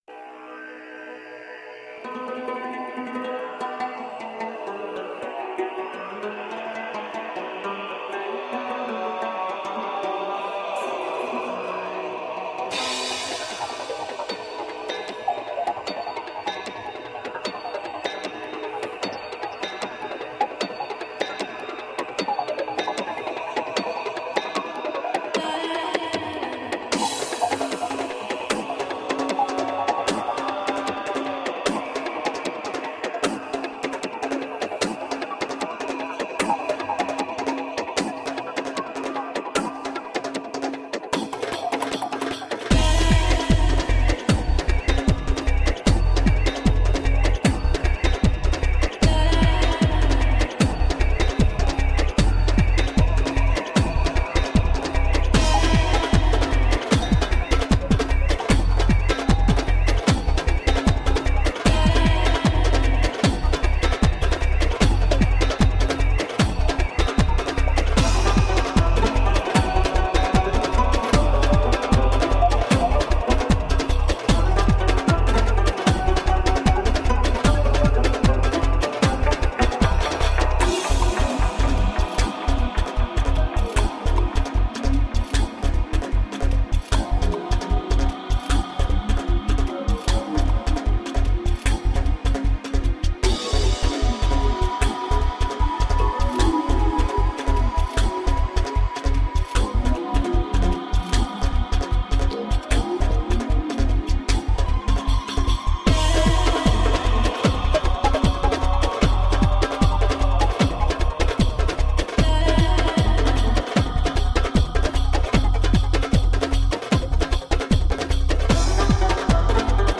Passionate eastern percussion.
didgeridoo